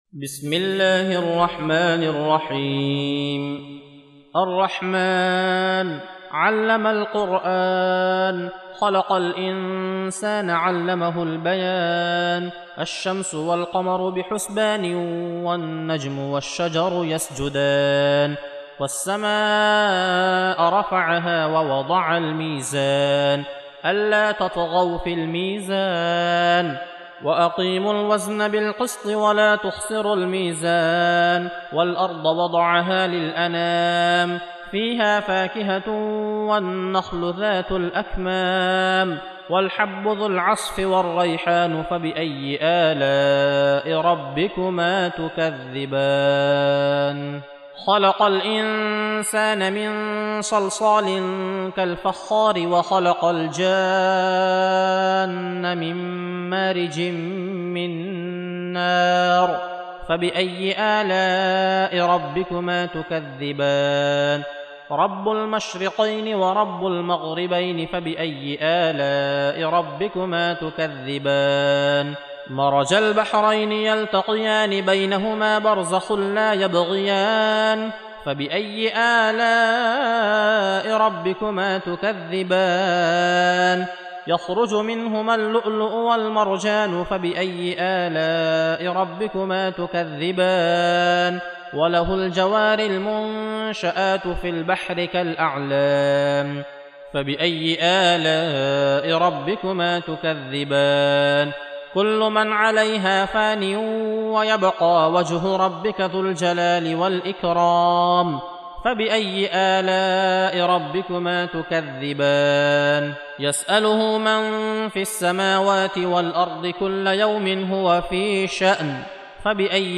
Surah Sequence تتابع السورة Download Surah حمّل السورة Reciting Murattalah Audio for 55. Surah Ar-Rahm�n سورة الرحمن N.B *Surah Includes Al-Basmalah Reciters Sequents تتابع التلاوات Reciters Repeats تكرار التلاوات